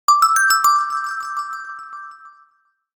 notification_001.ogg